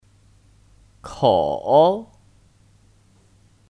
口 (Kǒu 口)